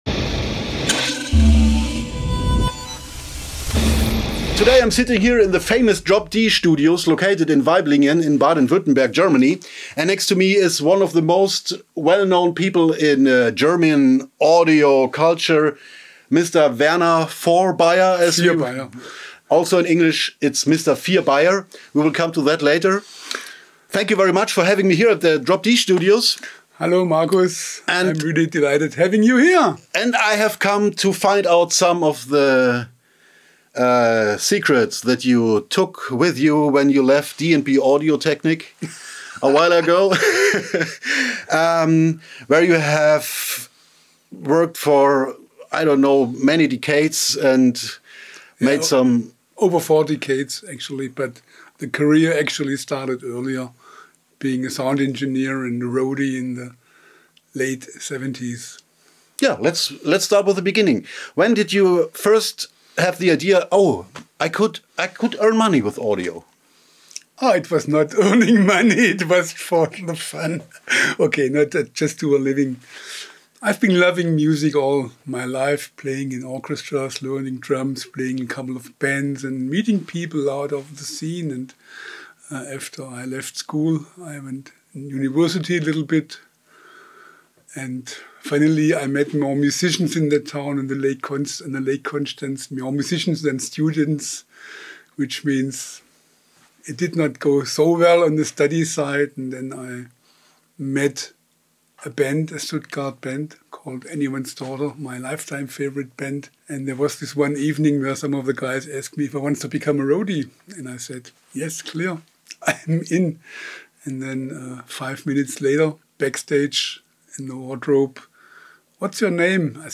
(Auf Englisch)